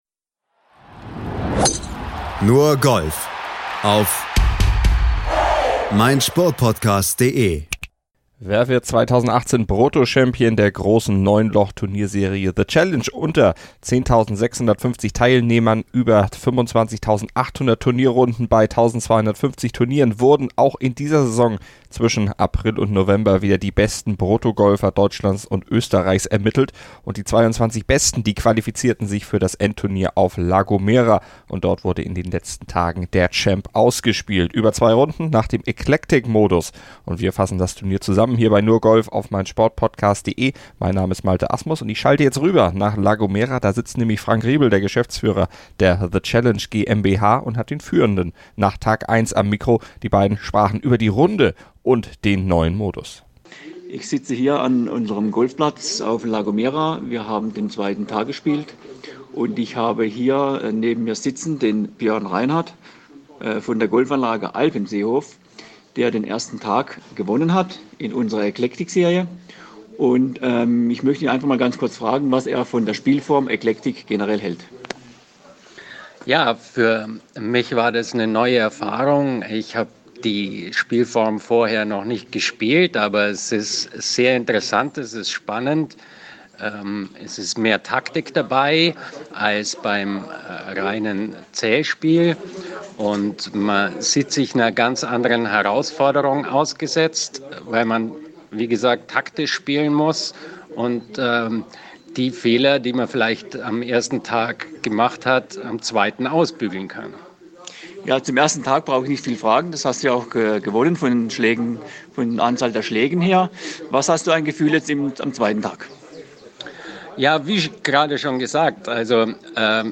Turnier zusammen und hat die Stimme des Siegers für euch.